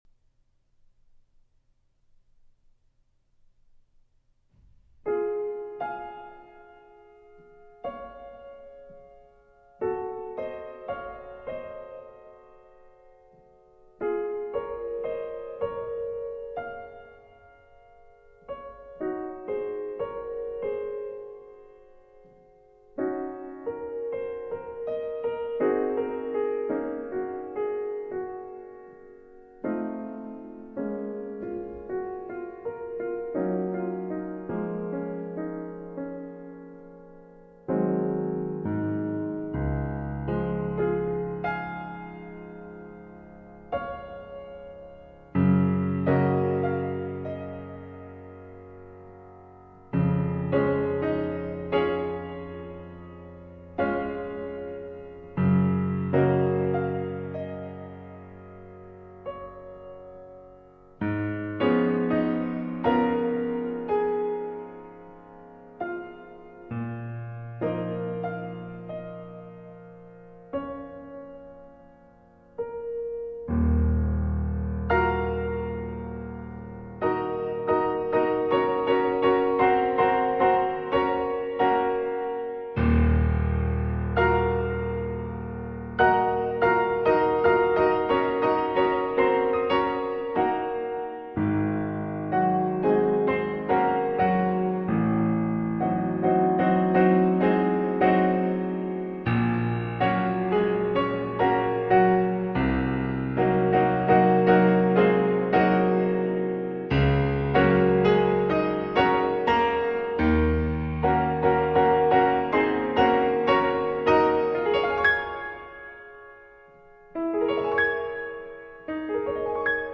Posłuchaj fantastycznych dźwięków z III części Suity fortepianowej:
Clair de lune piano